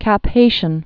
(kăp hāshən) or Cap-Ha·ï·tien (kä-pä-ē-syăɴ)